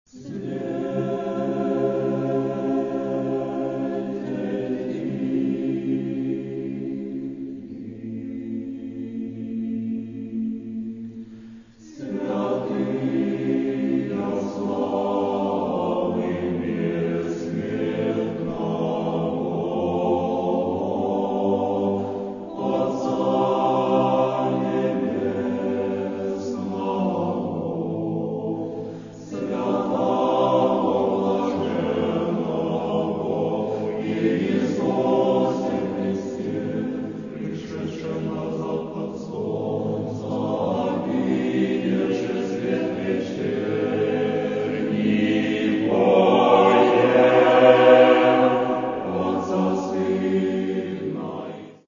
Церковная